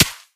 sand01gr.ogg